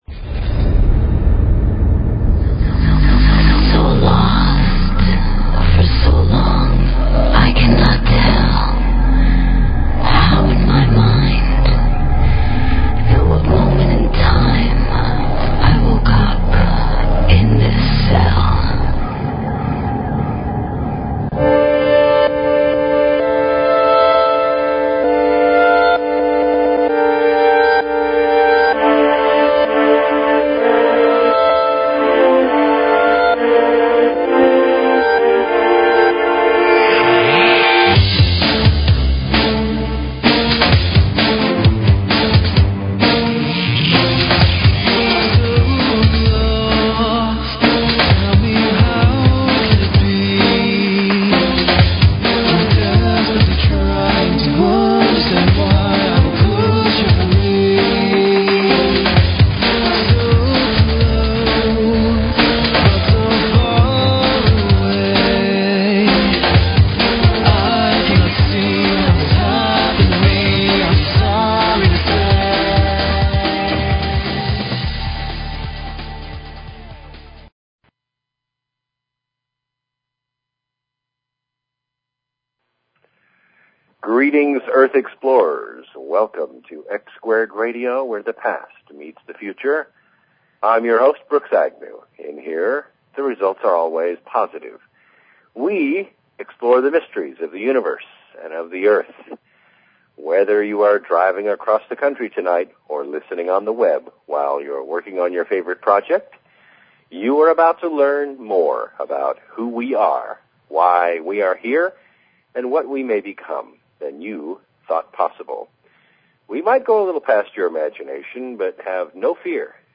Talk Show Episode, Audio Podcast, X-Squared_Radio and Courtesy of BBS Radio on , show guests , about , categorized as
This is the first interview of its kind on Earth. Don't miss this interview and your chance to call in and be part of the program.